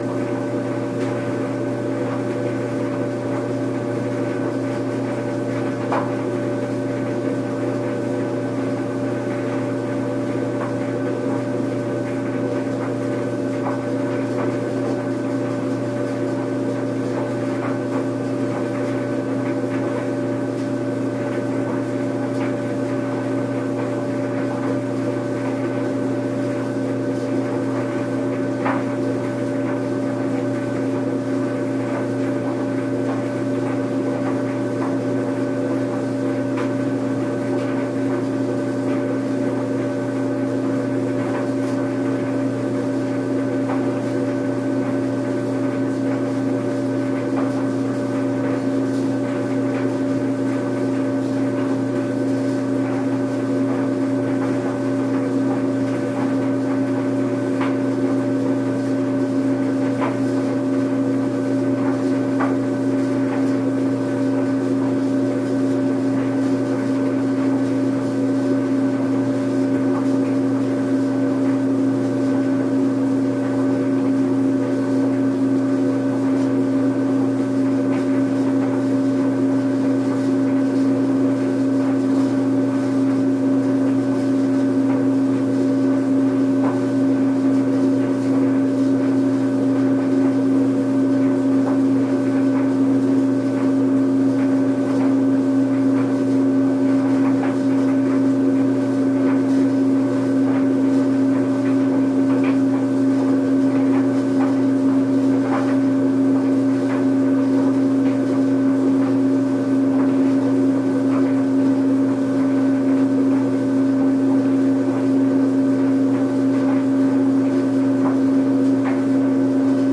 烘干机运行
描述：一台自动晾衣机在运行。衣服在翻滚。
Tag: 背景 噪声 衣服 无人驾驶飞机 干燥机 拟音 嗡嗡声 机械 金属 运行 运行 翻滚 氛围 翻滚 自动